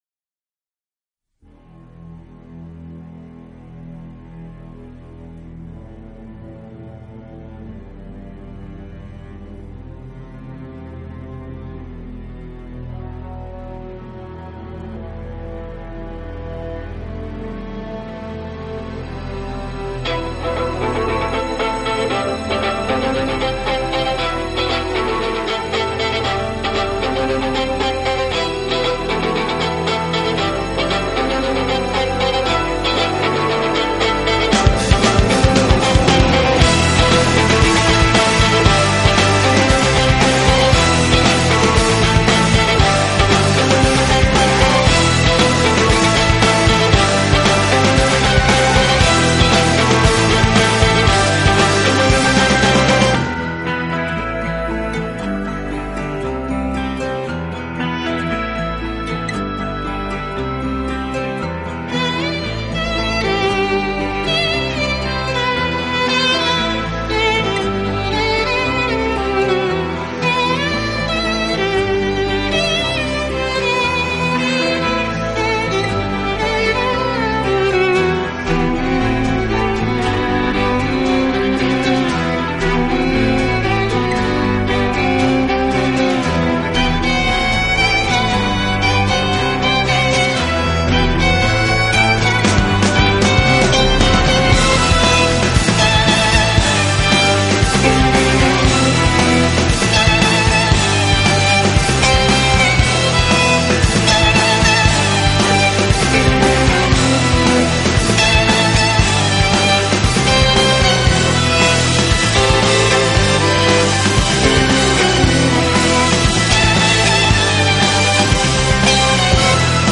【小提琴】